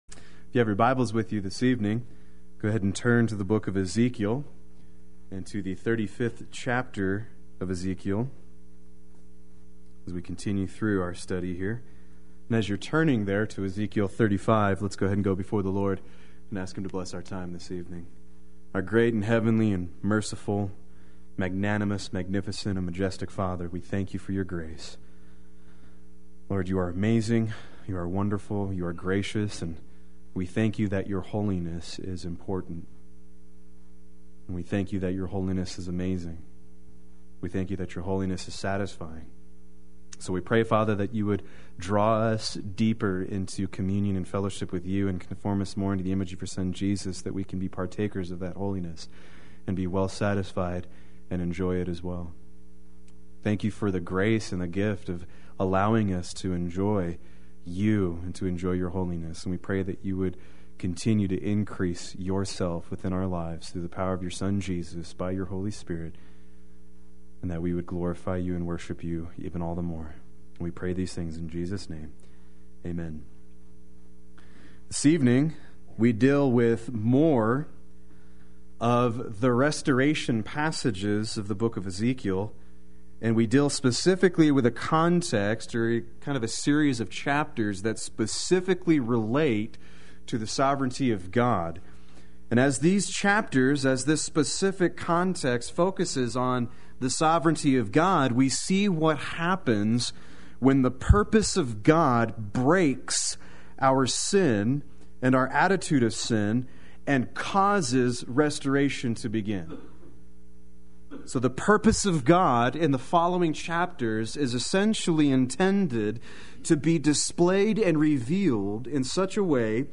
Play Sermon Get HCF Teaching Automatically.
Chapters 35-36 Wednesday Worship